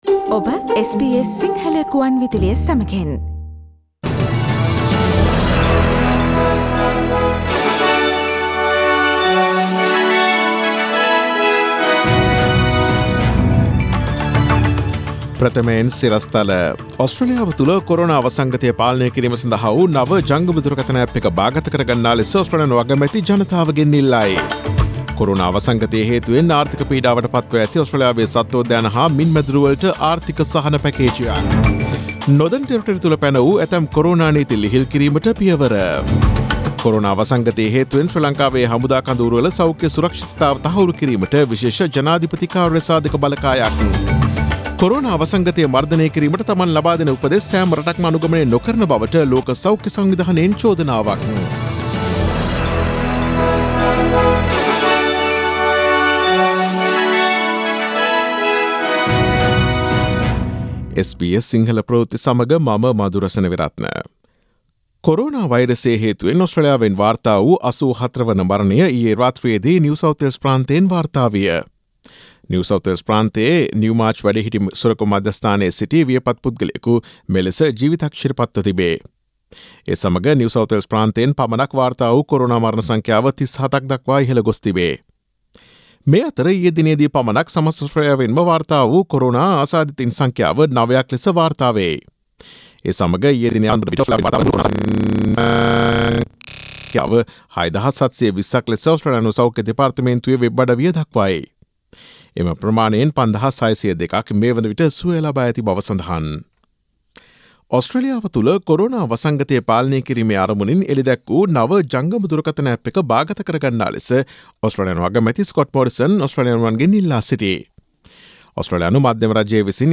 Daily News bulletin of SBS Sinhala Service: Tuesday 28 April 2020